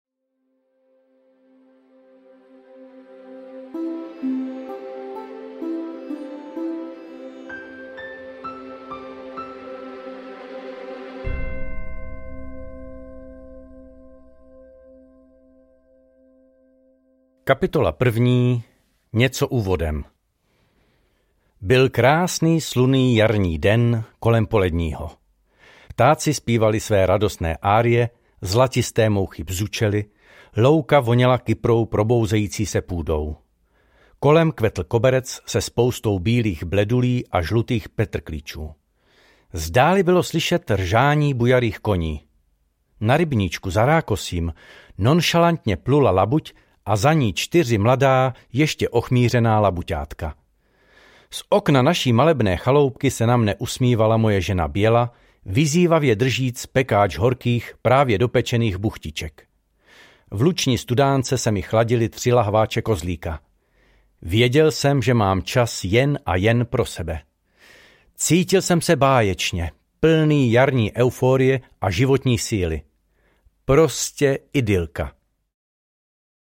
Každý uživatel může po zakoupení audioknihy daný titul ohodnotit, a to s pomocí odkazu zaslaný v mailu.